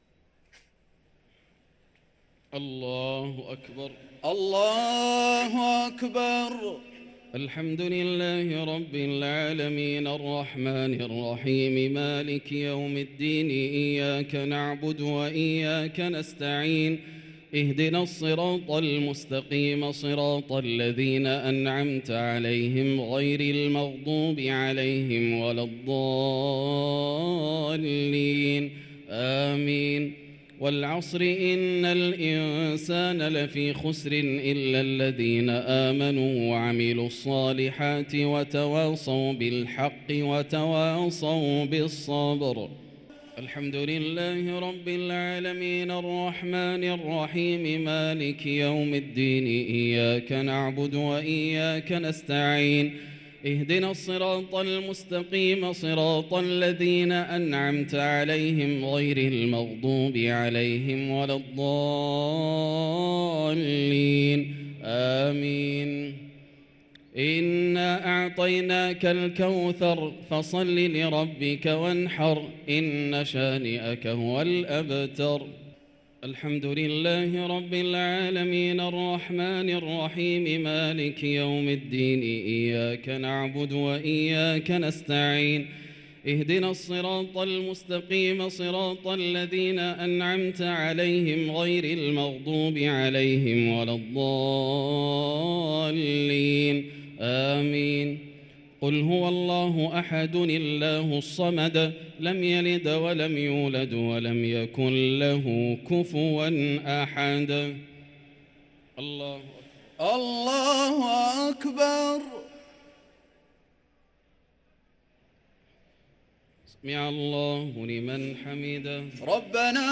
صلاة التراويح ليلة 15 رمضان 1444 للقارئ ياسر الدوسري - الشفع والوتر - صلاة التراويح